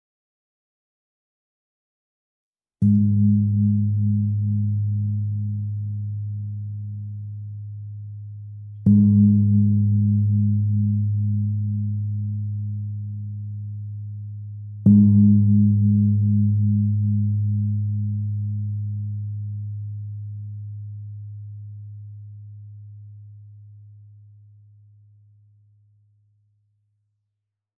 Meinl Sonic Energy 22" Thai Gong (THG22)